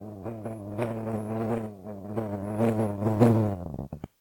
sounds_bumble_bee_02.ogg